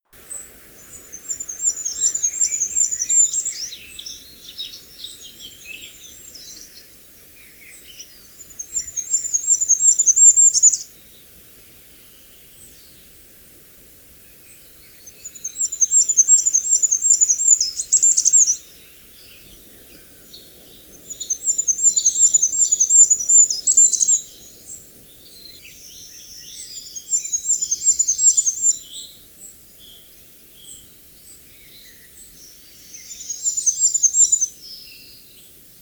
goudhaan
♫ zang
goudhaantje_zang.mp3